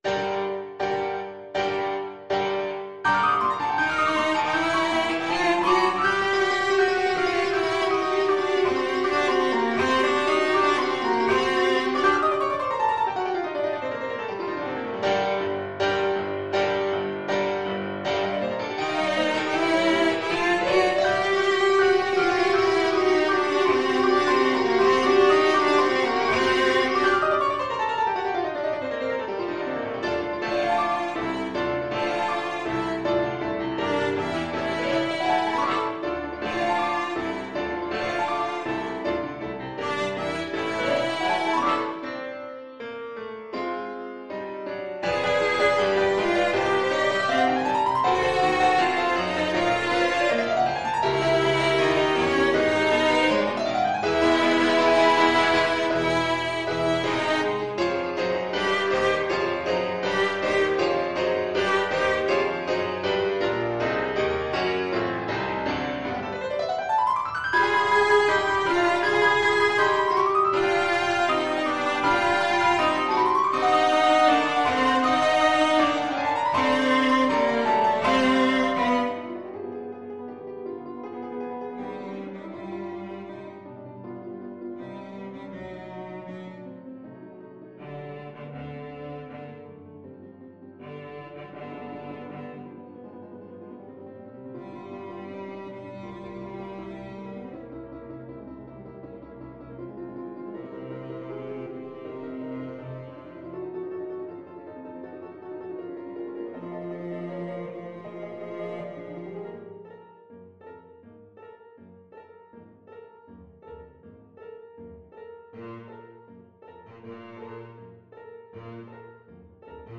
Classical Verdi, Giuseppe Dies Irae from Requiem Cello version
Cello
G minor (Sounding Pitch) (View more G minor Music for Cello )
4/4 (View more 4/4 Music)
Allegro agitato (=80) (View more music marked Allegro)
Classical (View more Classical Cello Music)